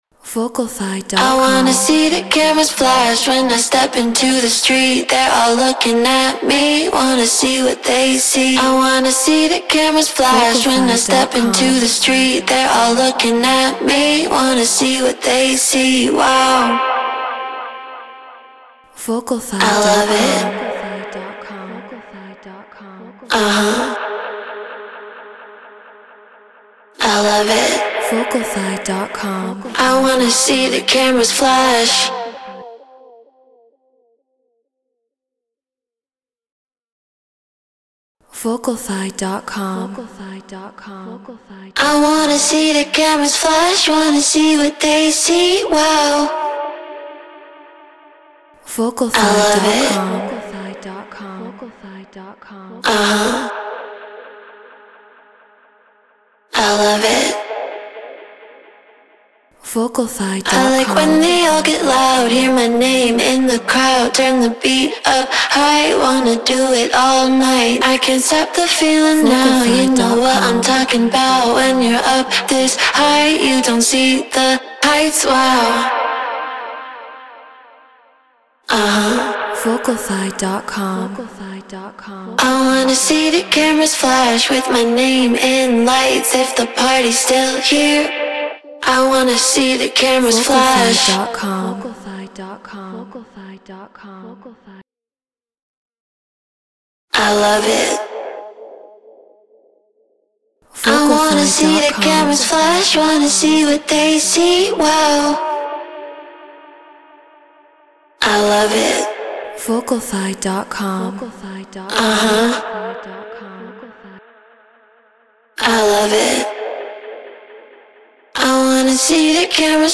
Tech House 130 BPM Cmin
Shure SM7B Scarlett 2i2 4th Gen Ableton Live Treated Room